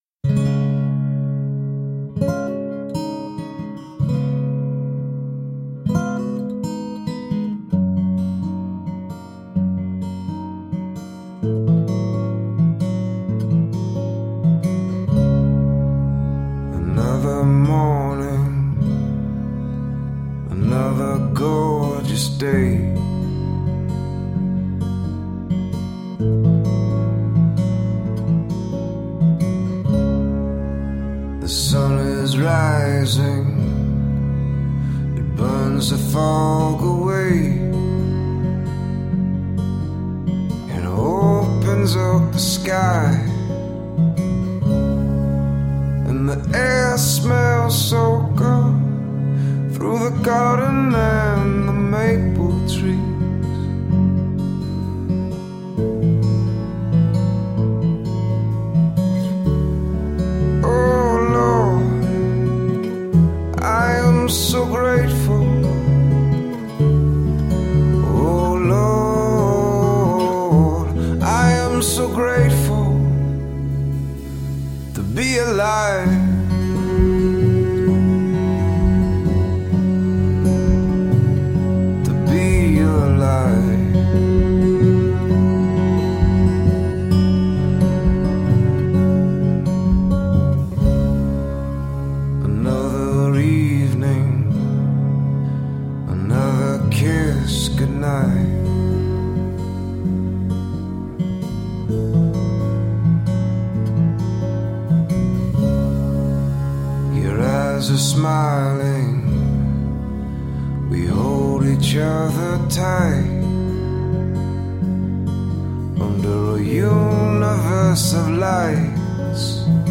Natural mix of rock, folk, and reggae.
the music has honesty, simplicity, and complexity